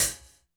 hihat01.wav